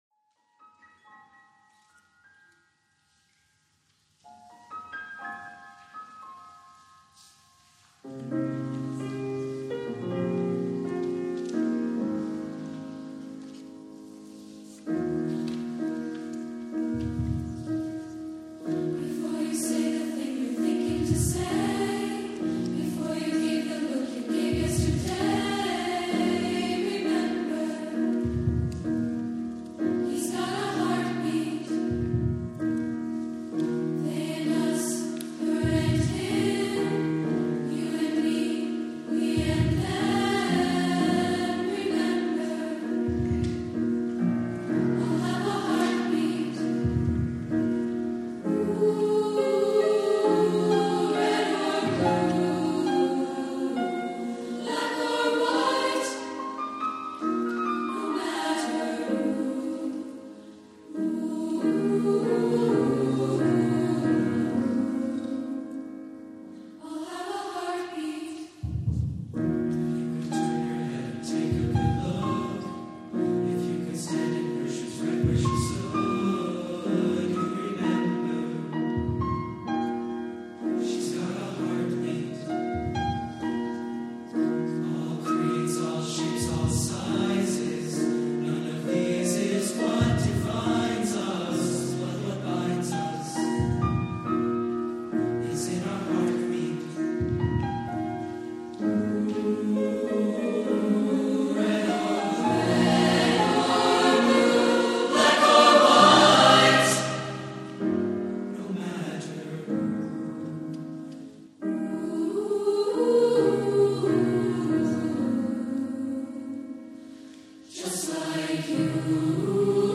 Appalachian Folk Song
Voicing: SATB